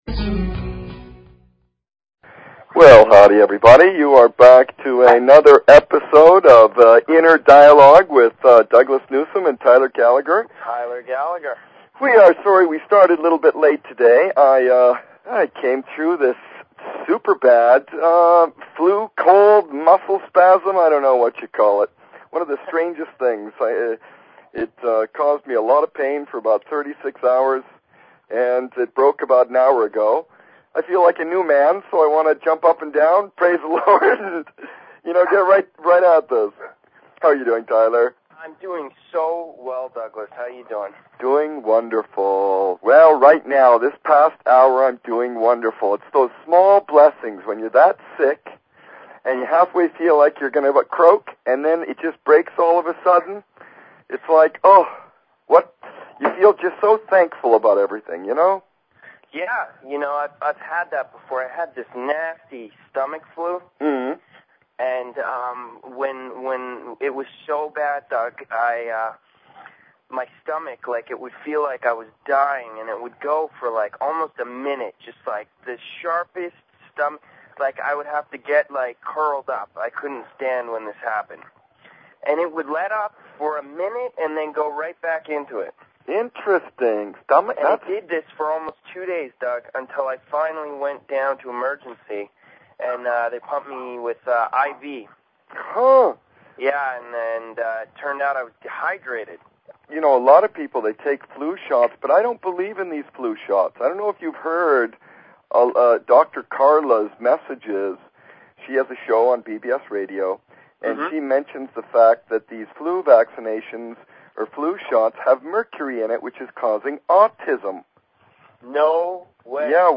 Talk Show Episode, Audio Podcast, Inner_Dialogue and Courtesy of BBS Radio on , show guests , about , categorized as